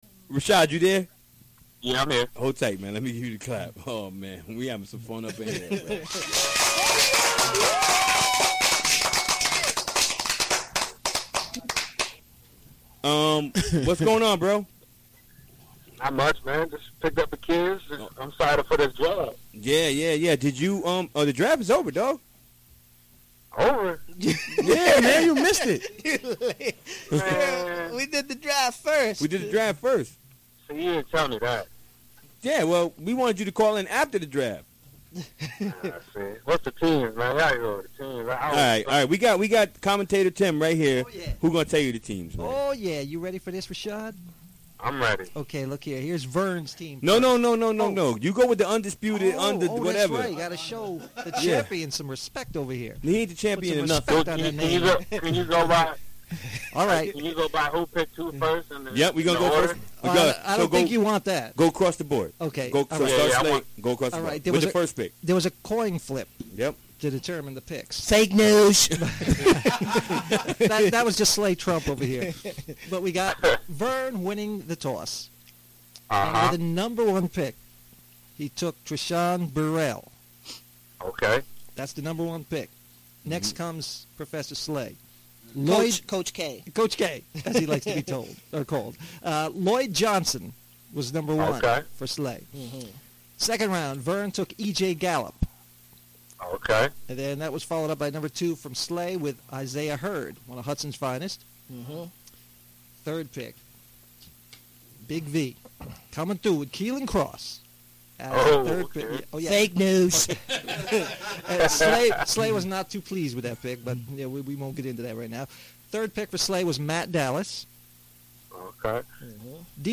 Recorded during the WGXC Afternoon Show Wednesday, February 22, 2017.